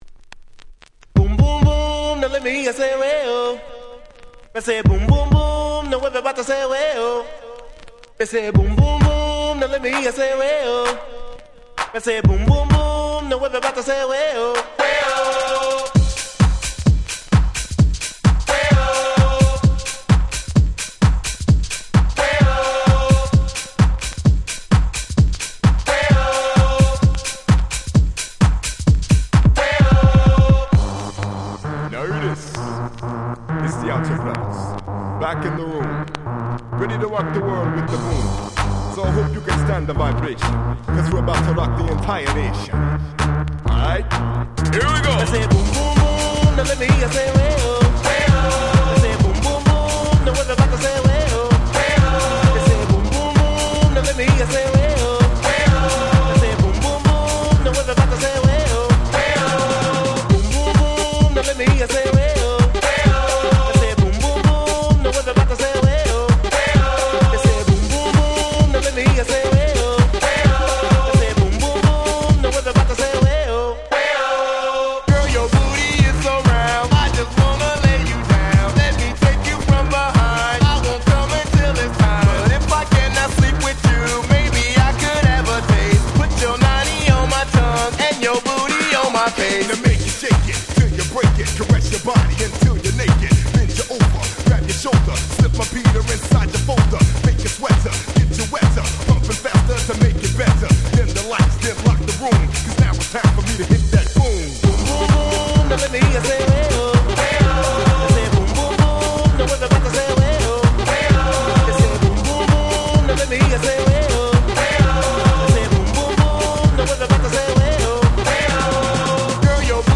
95' Super Hit Euro Dance / Dance Pop !!
「ブンブンブーン！」のキャッチーなサビで大ヒット！！
90's ダンスポップ